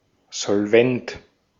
Ääntäminen
Synonyymit flüssig zahlungsfähig liquid Ääntäminen Tuntematon aksentti: IPA: [zɔlˈvɛnt] Haettu sana löytyi näillä lähdekielillä: saksa Käännös 1. solvente Esimerkit Das Unternehmen ist nicht mehr solvent.